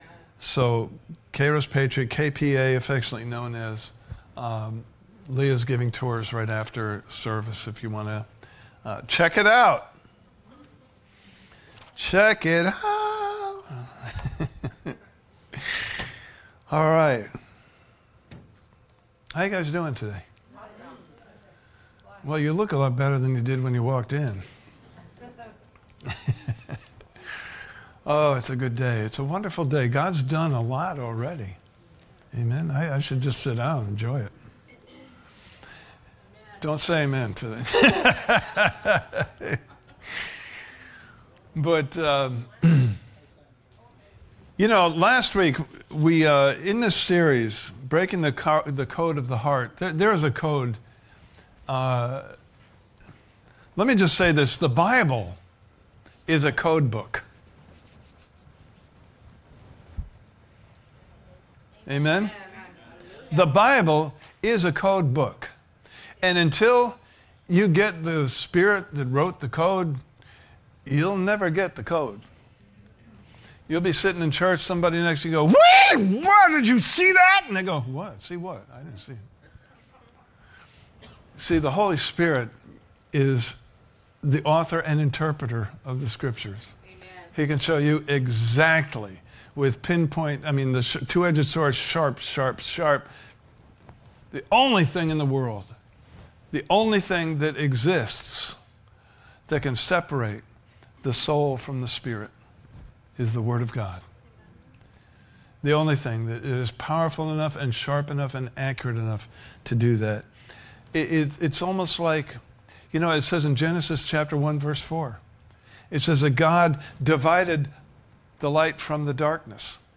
Series: Breaking the Code of the Heart Service Type: Sunday Morning Service « Part 2